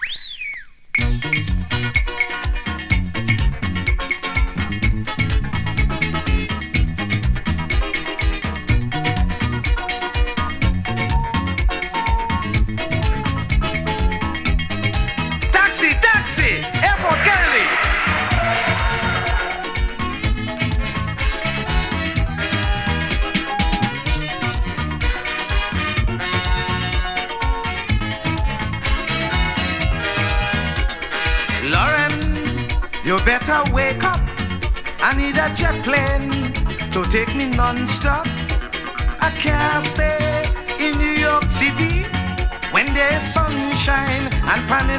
ひたすらに楽しさ溢れてます。
World, Soca　France　12inchレコード　33rpm　Stereo